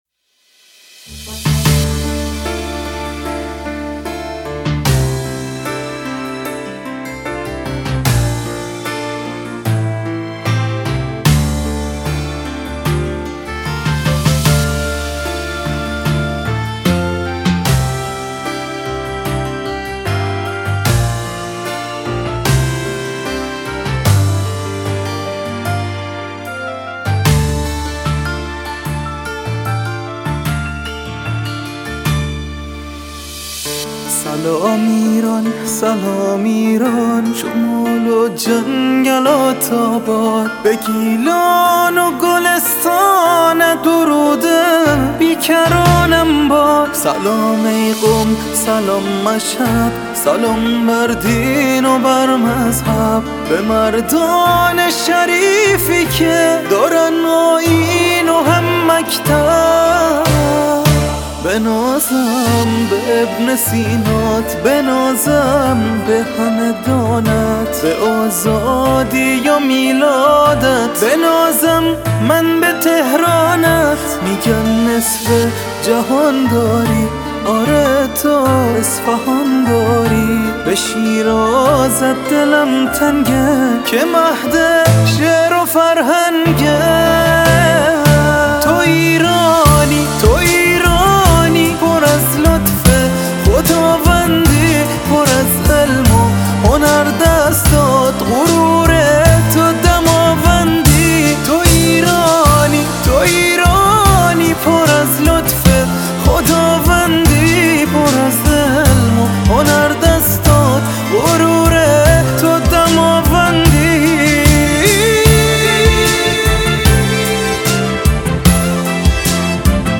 ملایم
بعضی جاها لهجه بندری داره